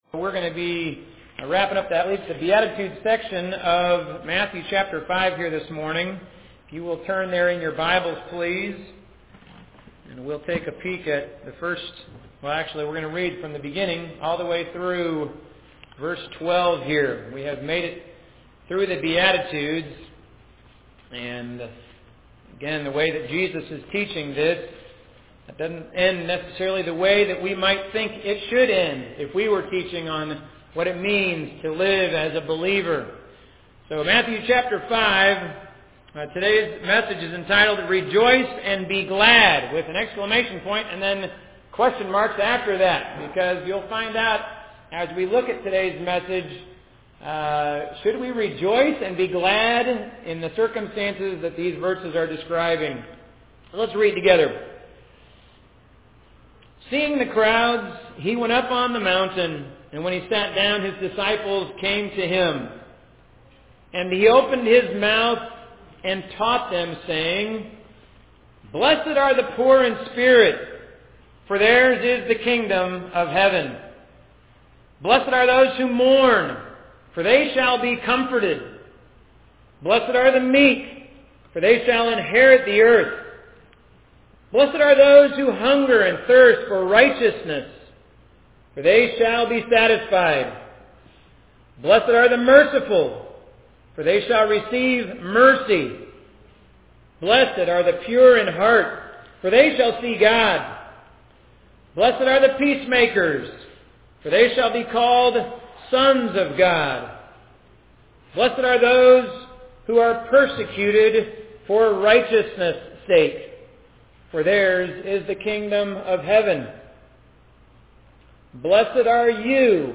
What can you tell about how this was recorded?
Audio Sermons from Wallace Street Evangelical Church